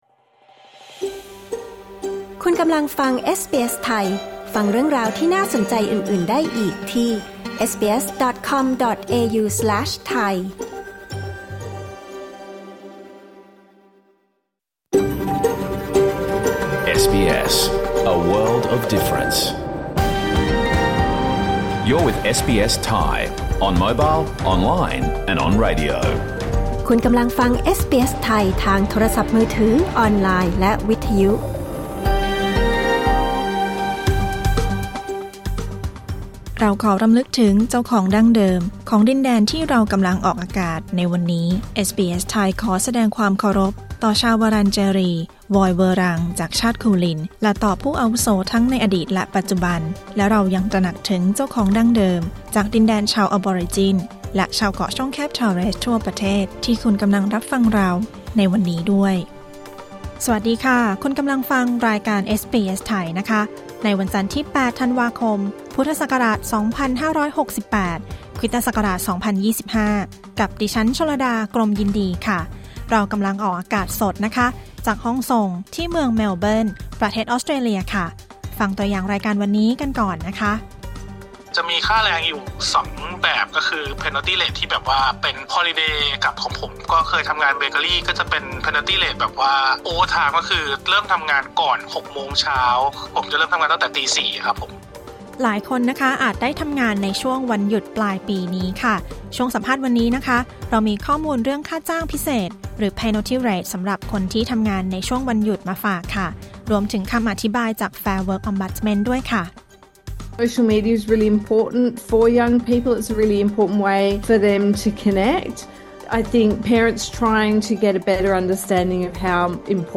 รายการสด 8 ธันวาคม 2568